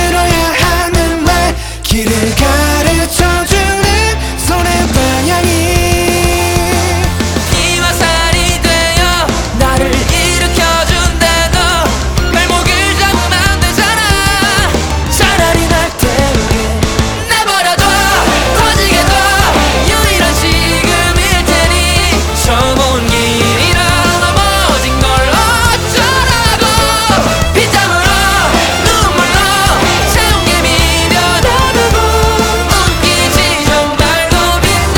Жанр: Поп / Рок / K-pop